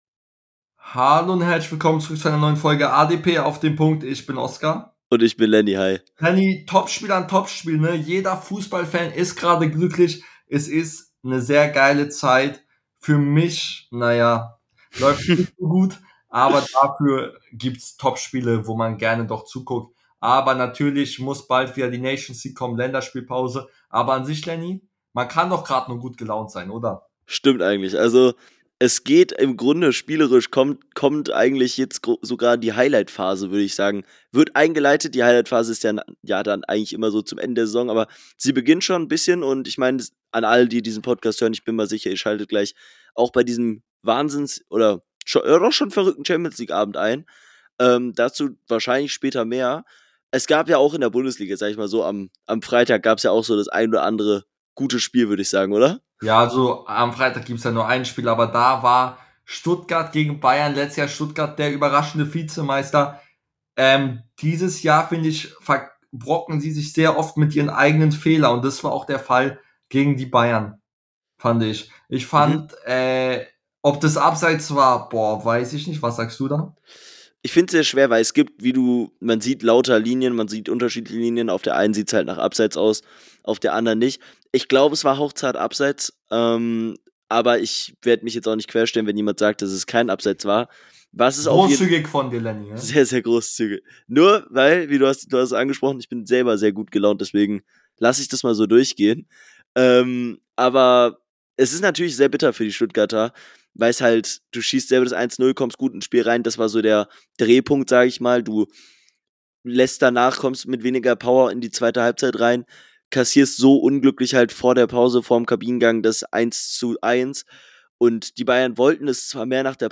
In der heutigen Folge reden die beiden Hosts über die Topspiele des vergangenen Bundesligawochenende , blicken auf den deutschen Championsleague Kracher, wundern sich über Herta und vieles mehr